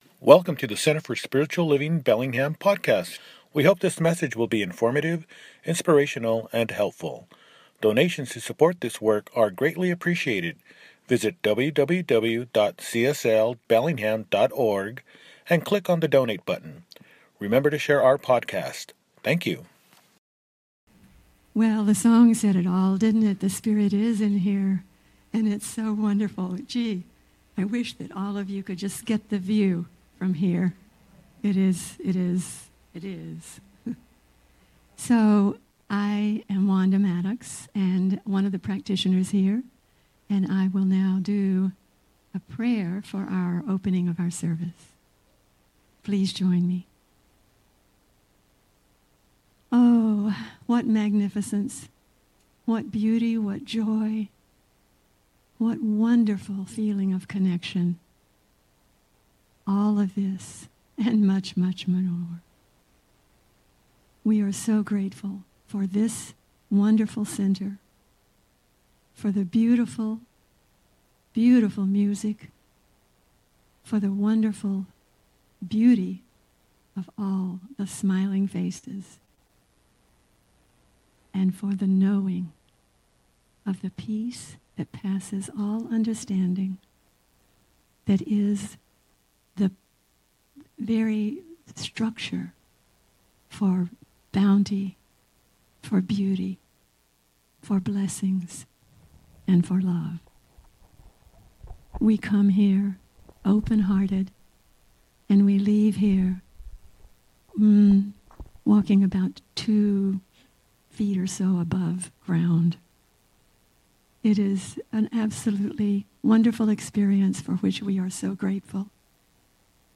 Dec 21, 2025 | Podcasts, Services